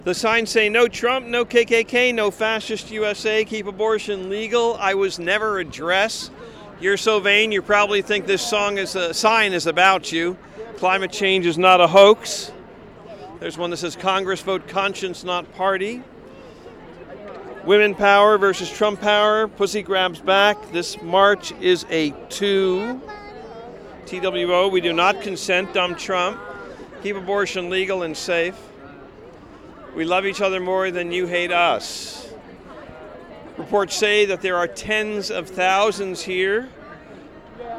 READS SOME OF THE SIGNS IN THE CROWD.